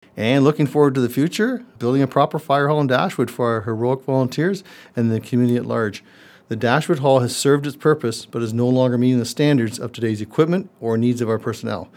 As we look to the new year, South Huron Mayor George Finch stopped by the myFM studio and highlighted the community’s accomplishments in 2024, reflecting on a year filled with resilience, collaboration, and growth.